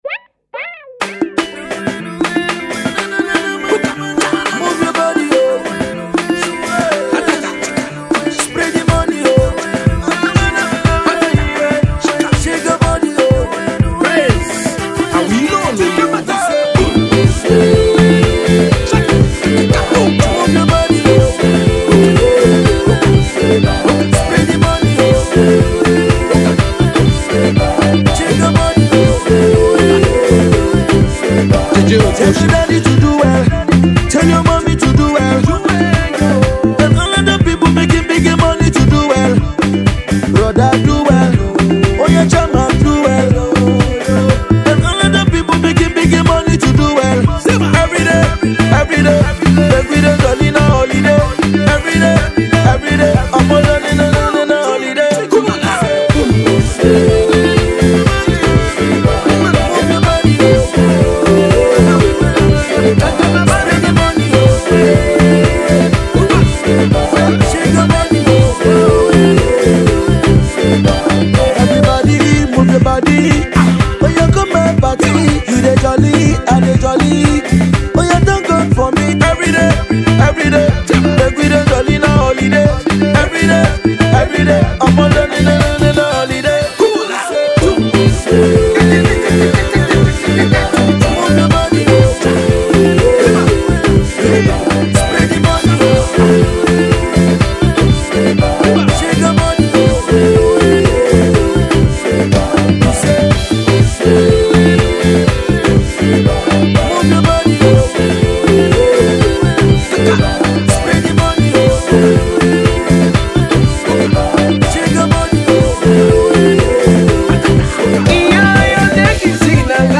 upbeat song
R&B/Pop